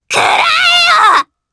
Viska-Vox_Attack3_jp.wav